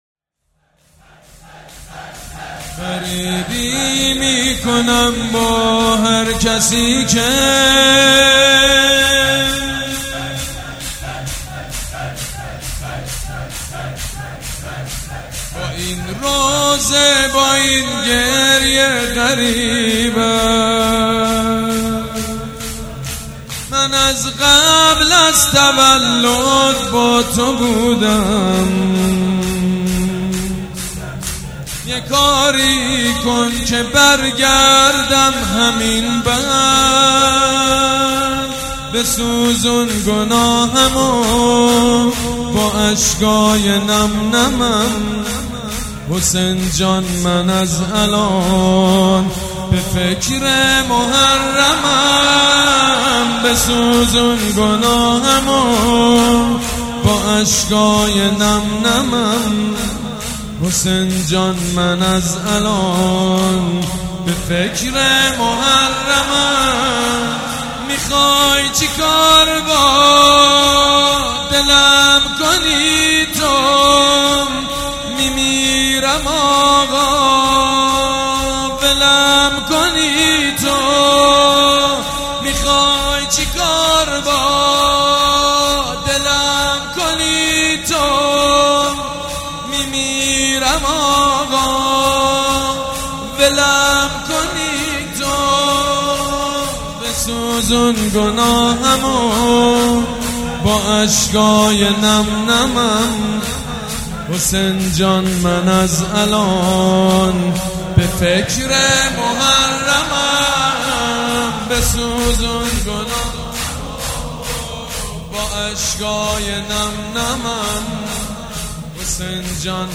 «شهادت امام صادق 1397» شور: غریبی میکنم با هر کسی که